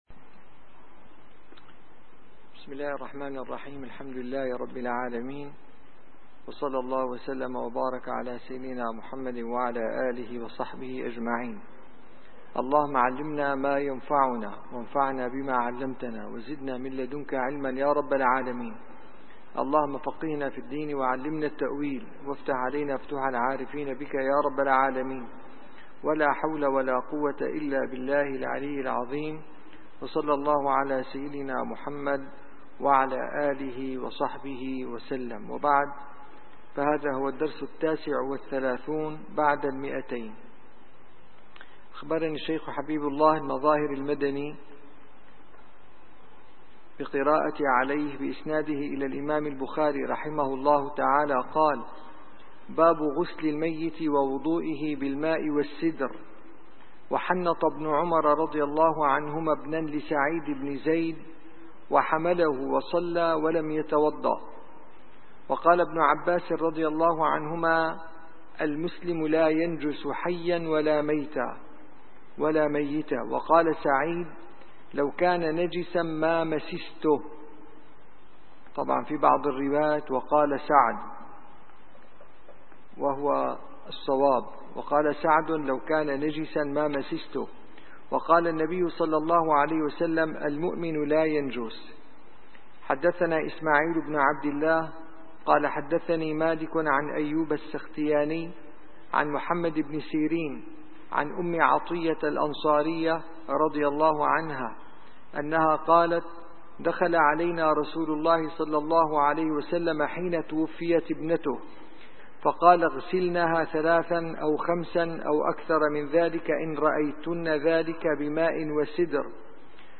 - الدروس العلمية - شرح صحيح البخاري - كتاب الجنائز الحديث 1253 - 1264